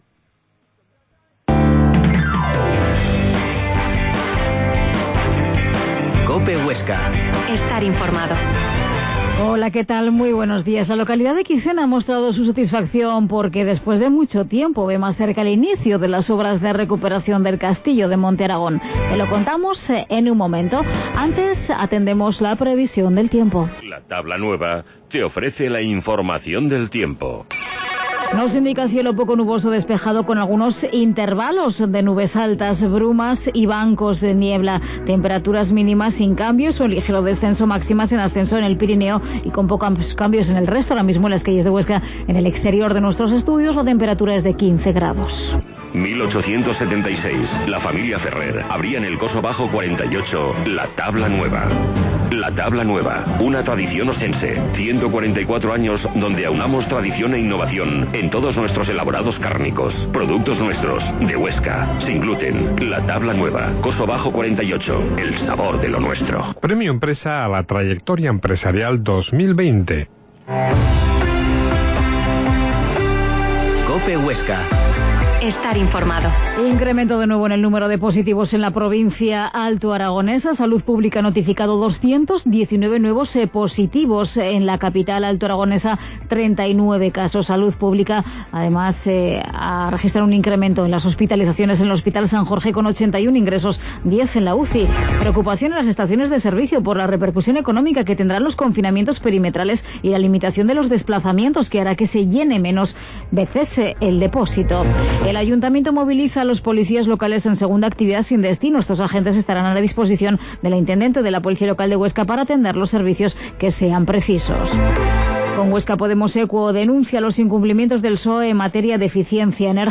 Herrera en COPE Huesca 12.50h Entrevista al alcalde de Quicena, Javier Belenguer